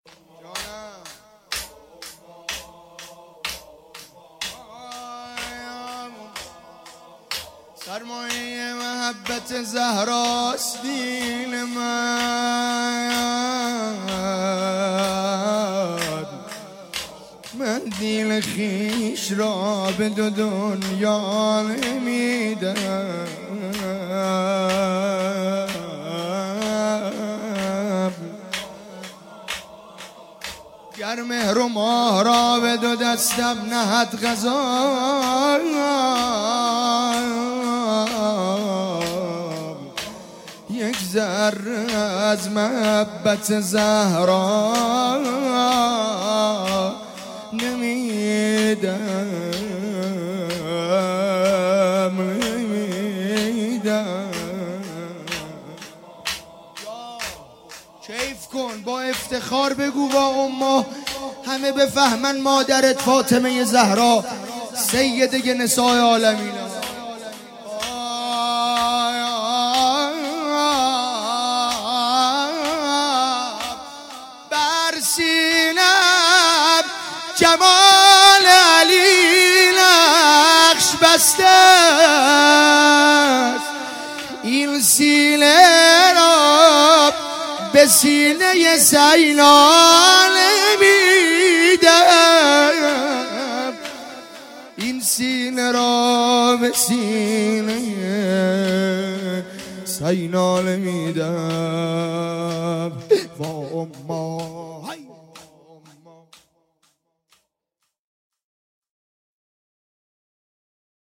روضه انصارالزهرا سلام الله علیها